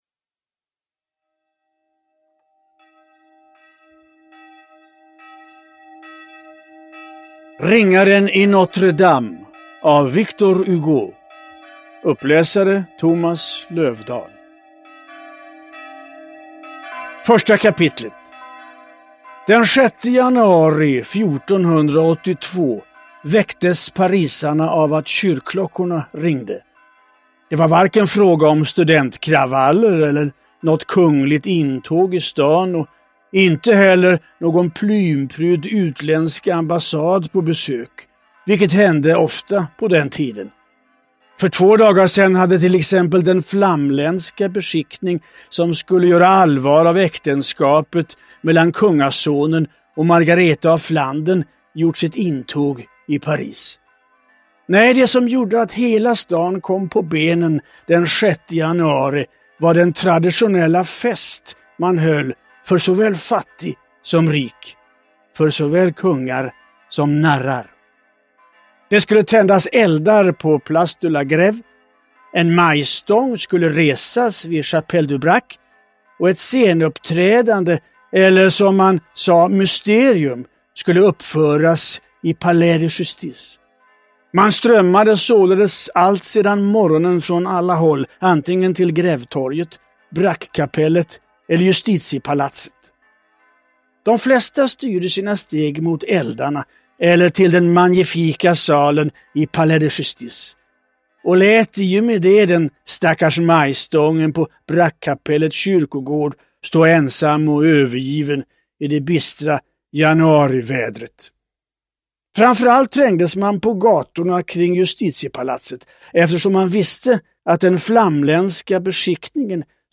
Ljudbok
Victor Hugos stora mästerverk som ljudbok.